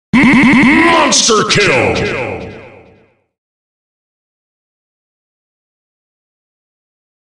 • Качество: 128, Stereo
голосовые